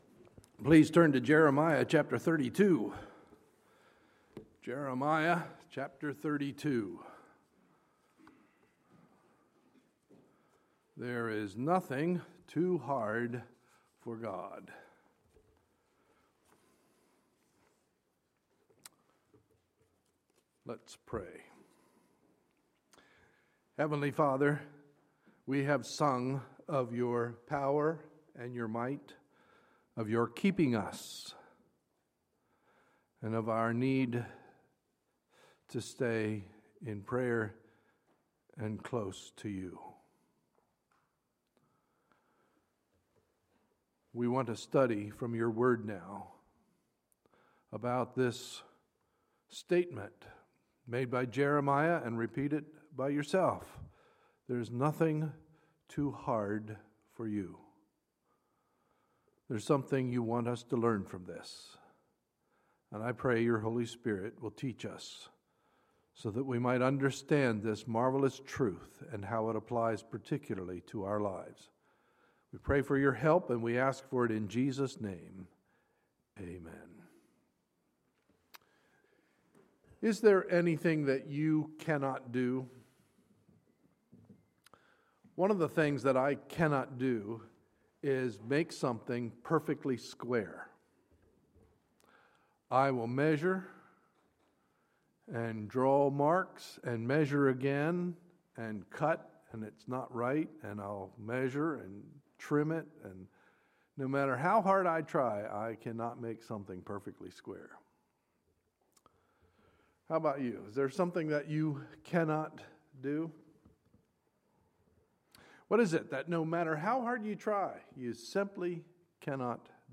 Sunday, October 11, 2015 – Sunday Morning Service